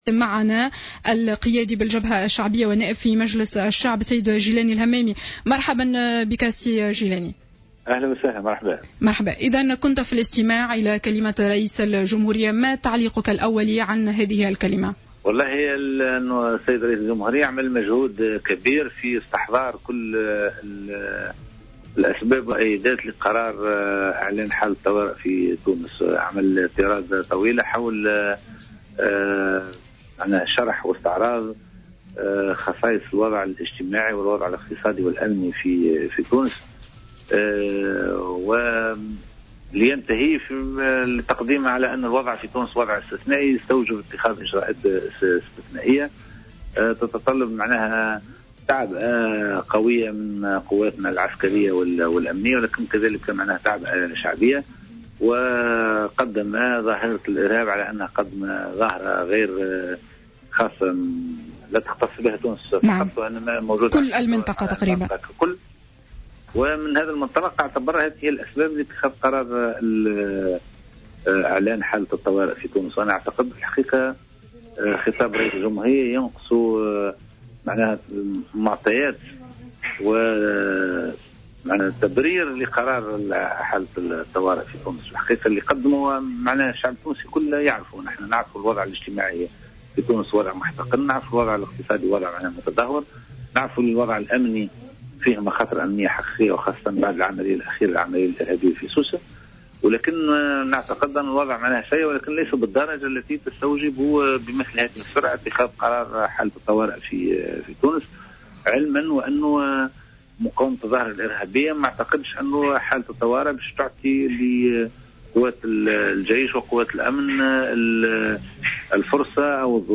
اعتبر الجيلاني الهمامي القيادي في الجبهة الشعبية في تصريح لجوهرة أف أم أمس السبت 04 جويلية 2015 أن خطاب رئيس الجمهورية الذي توجه به أمس الى الشعب ينقصه المعطيات والتبرير لأسباب اتخاذ قرار الإعلان عن حالة الطوارئ في البلاد.